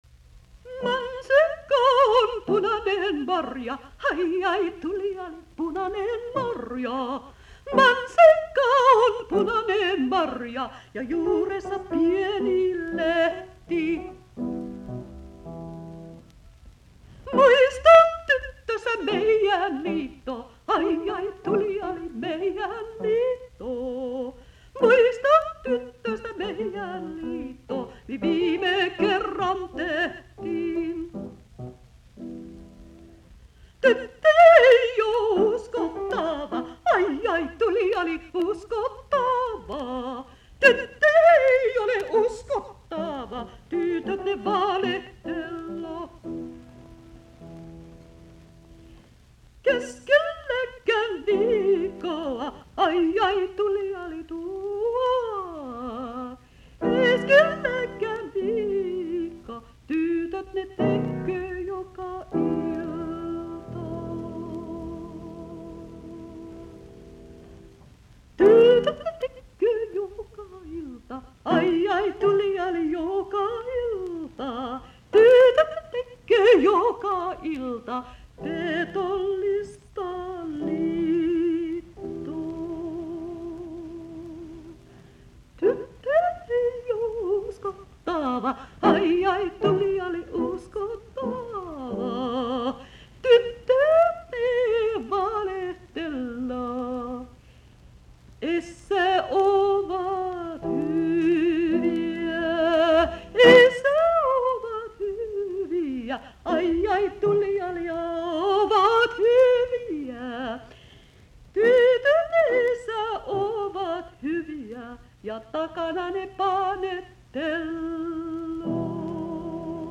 musiikkiäänite